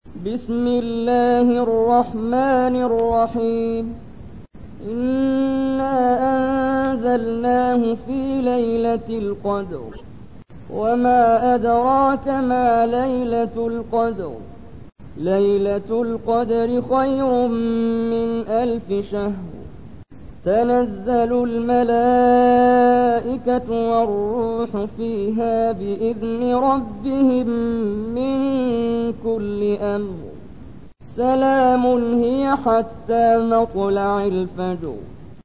تحميل : 97. سورة القدر / القارئ محمد جبريل / القرآن الكريم / موقع يا حسين